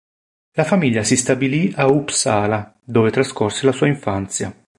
in‧fàn‧zia
/inˈfan.t͡sja/